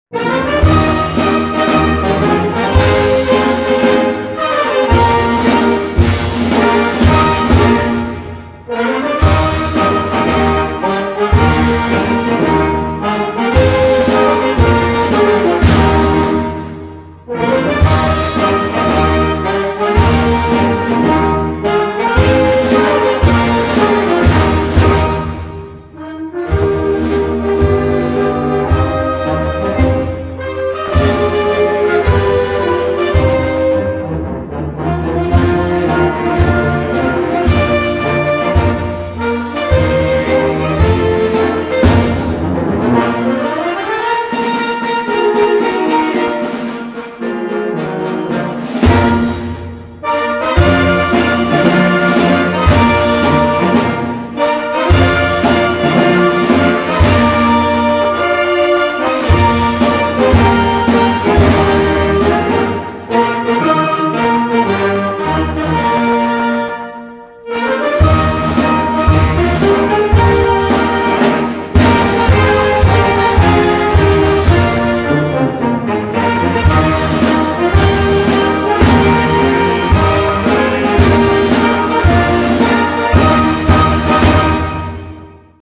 Himno1instrumental.mp3